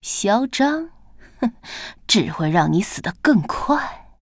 文件 文件历史 文件用途 全域文件用途 Cyrus_fw_01.ogg （Ogg Vorbis声音文件，长度4.2秒，101 kbps，文件大小：52 KB） 源地址:游戏语音 文件历史 点击某个日期/时间查看对应时刻的文件。